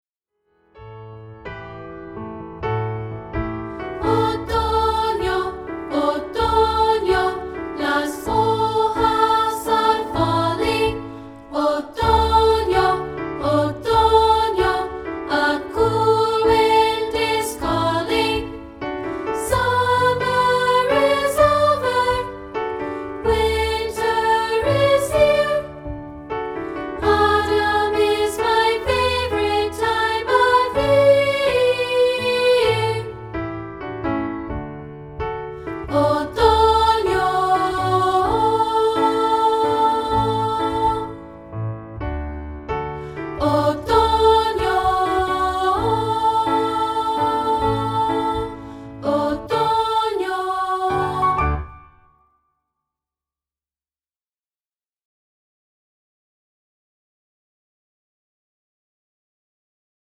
This track is part 2, isolated.